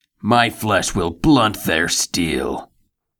“Equalization” to push up the bass, treble and pull down the middle frequencies.
A bit of short echo + some very bright reverb.
A bit of dynamic compression.
Pitch shifted down by 2 semitones.
This was bunged together in a couple of minutes just as a demo.